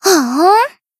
BA_V_Mika_Battle_Shout_3.ogg